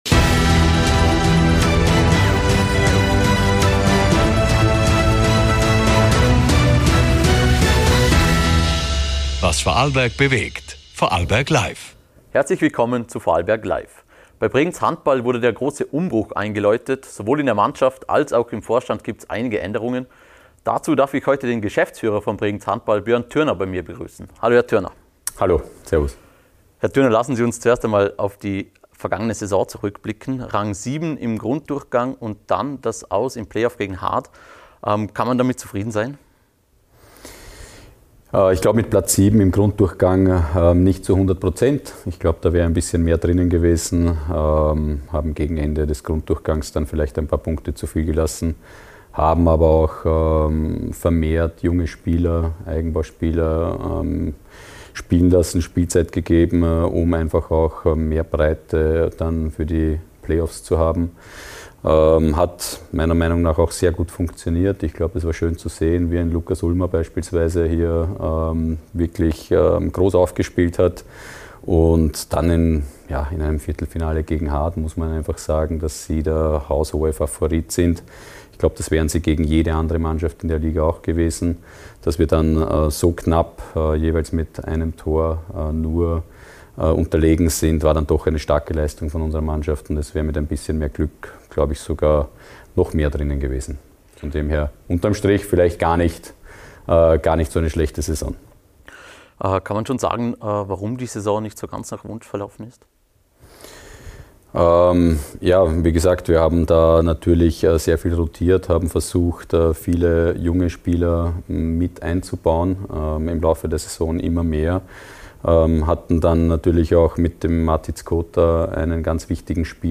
Interview ~ Vorarlberg LIVE Podcast